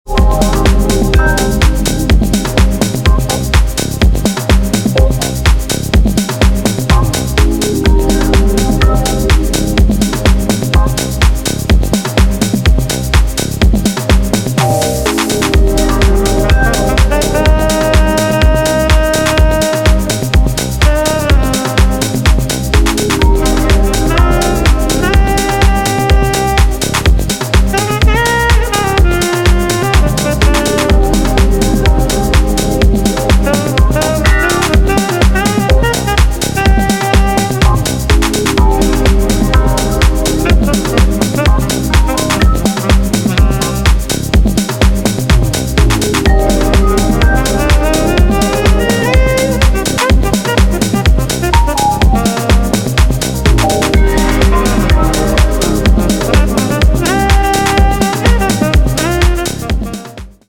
Nice funk driven house
Disco House